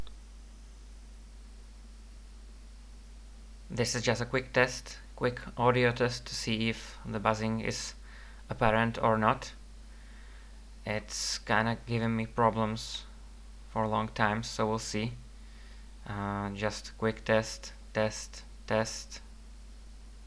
Squeaking noise randomly appearing in recording - Windows - Audacity Forum
There is a faint constant 50Hz buzz on that …
I can only hear the buzzing (in speakers) at lower than reasonable volume. Otherwise the hissing noise overpowers the buzz.
Yes, there is some very low volume 50Hz power hum in there (and 100 as well), and I can hear some very low volume data noises, but all of those sounds are lower volume than the normal microphone hiss ffffffffffff.
It’s a tiny pop at the beginning of the clip.
That area of the clip is supposed to be pure, simple room noise.